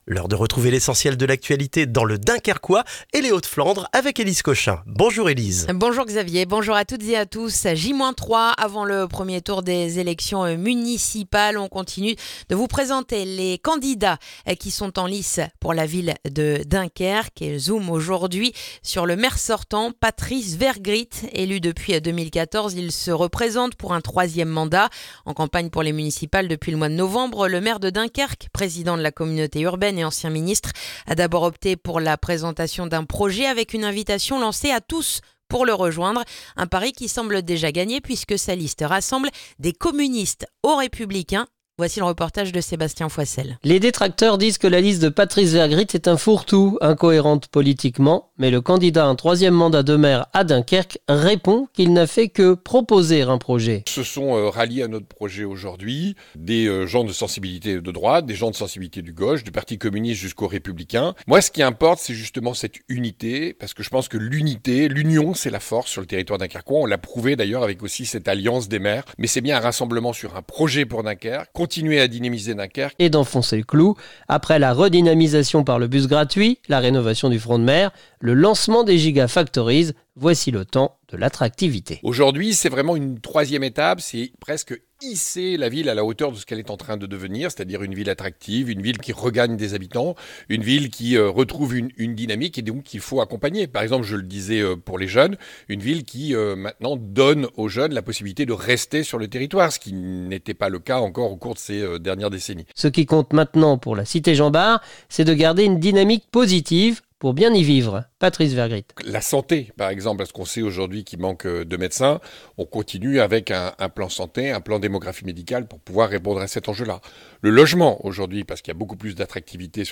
Le journal du jeudi 12 mars dans le dunkerquois